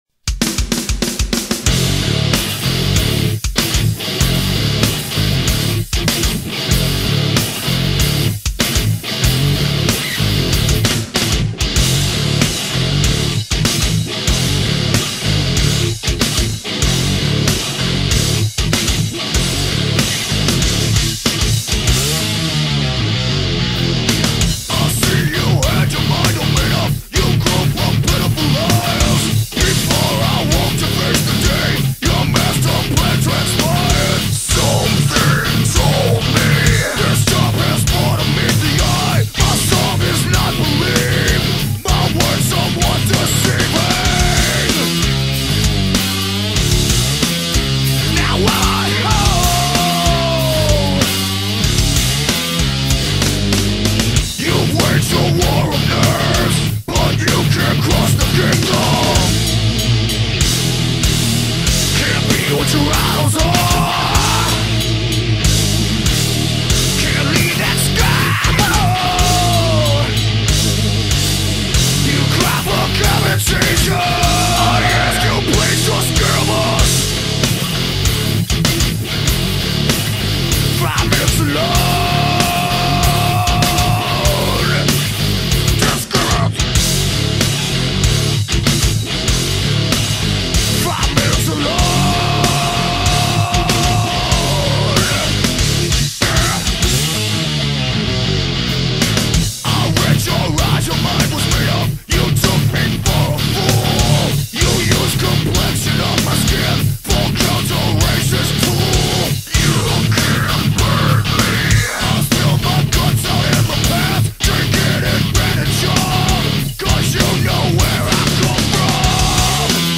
Groove Metal, Hardcore punk